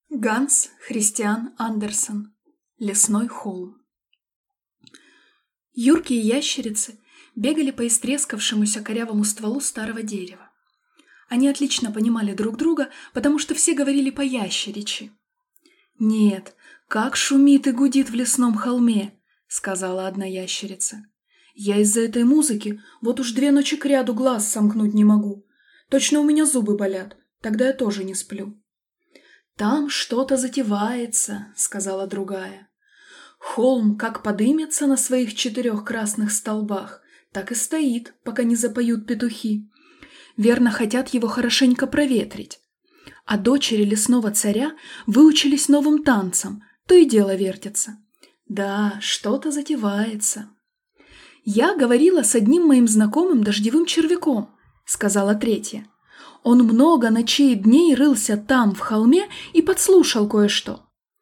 Аудиокнига Лесной холм | Библиотека аудиокниг